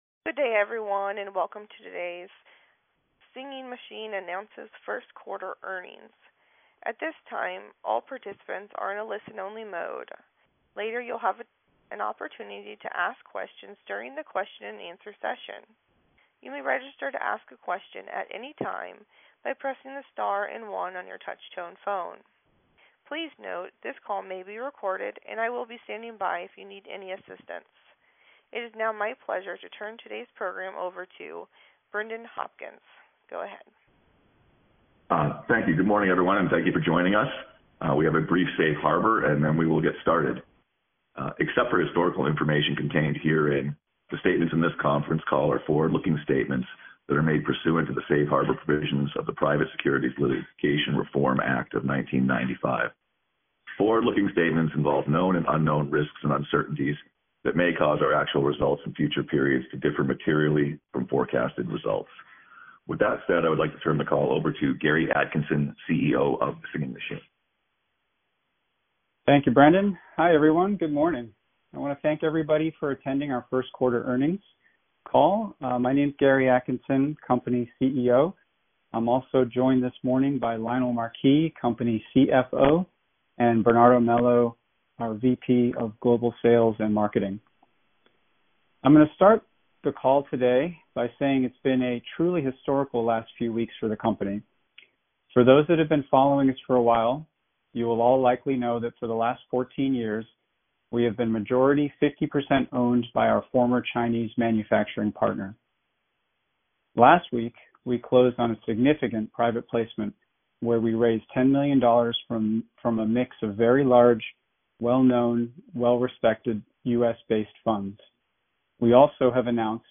Singing Machine Co Inc — SMDM Q1 2022 Earnings Conference Call